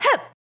attack2.wav